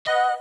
Index of /phonetones/unzipped/LG/A200/Keytone sounds/Sound2
Pound.wav